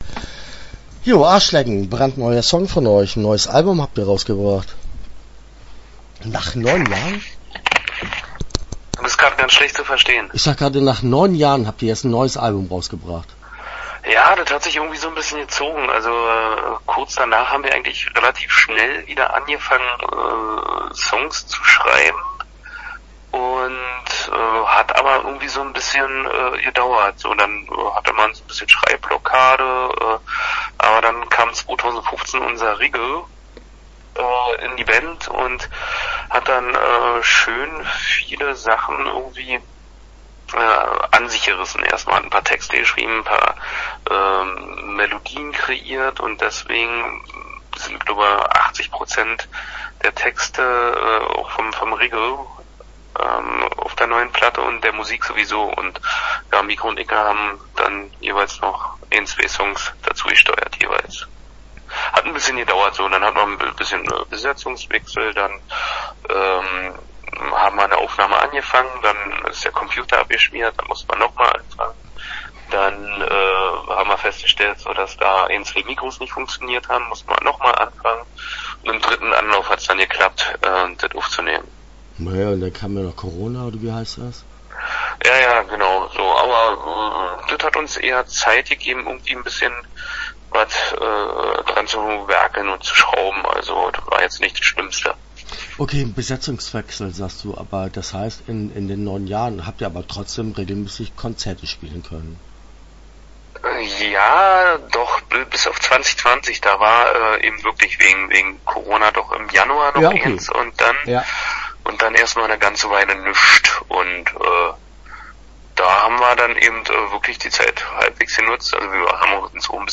Bockwurschtbude - Interview Teil 1 (11:30)